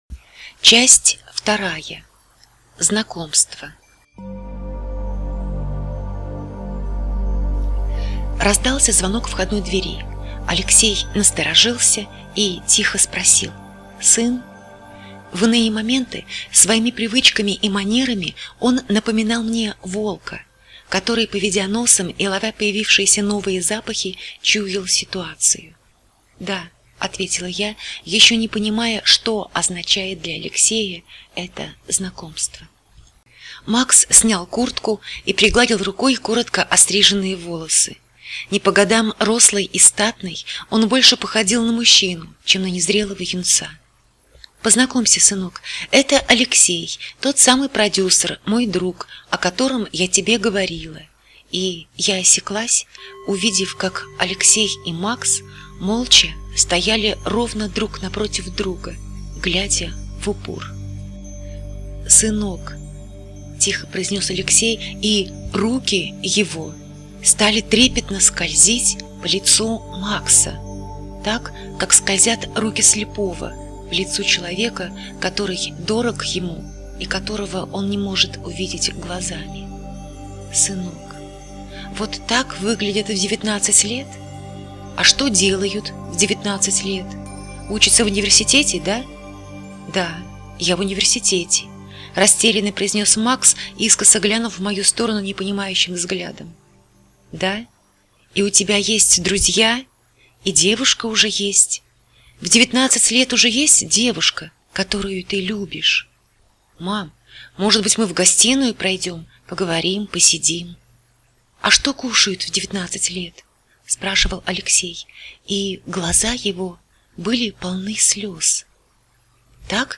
Аудиокнига: Красная собака или Переплетение случайностей